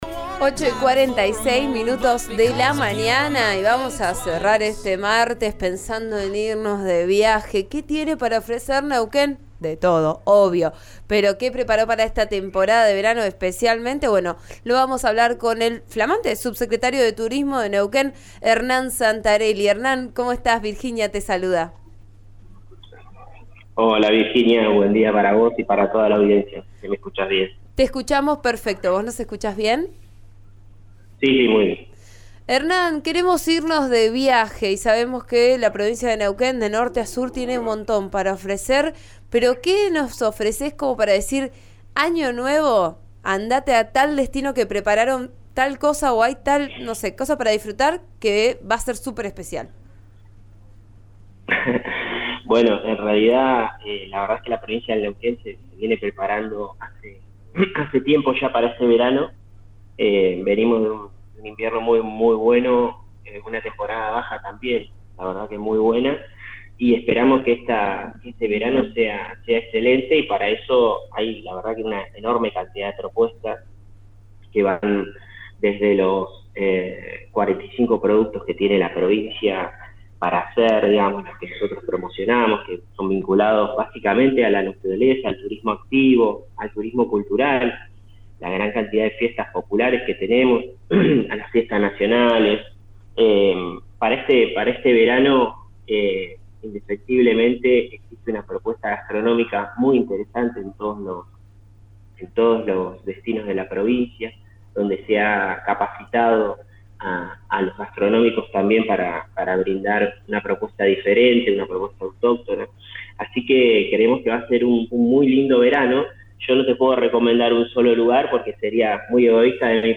En diálogo con 'Vos a Diario' por RÍO NEGRO RADIO, insistió en reservar con anticipación, antes de llegar a destino.
El subsecretario de Turismo de Neuquén, Hernán Santarelli, contó en «Vos a Diario», por RÍO NEGRO RADIO, cómo se prepara la temporada de verano en Neuquén. Explicó que por el «efecto Qatar 2022» las reservas empezaron a concretarse recién estas últimas semanas, pero que ya alcanzaron entre un 60 y un 70% en la provincia.